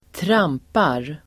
Uttal: [²tr'am:par]